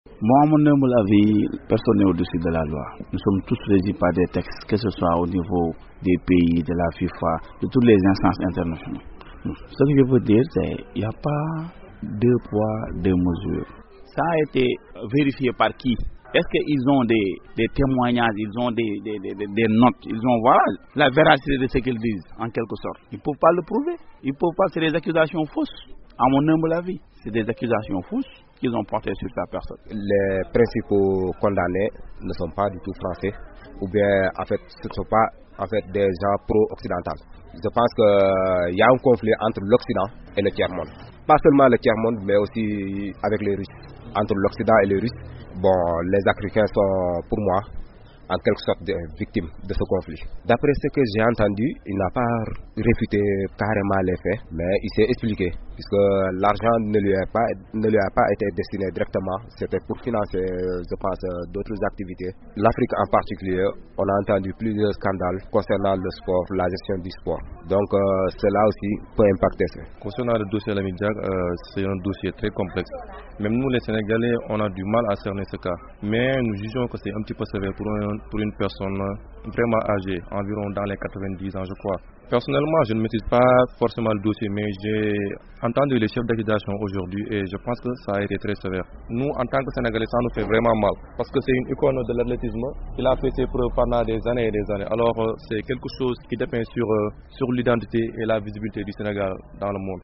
Au Sénégal, les réactions sont partagées entre de la compassion sur âge avancé de Lamine Diack 87 ans et les doutes Soulèves par son fils sur la transparence de l'enquête. Le micro trottoir de notre correspond à Dakar ...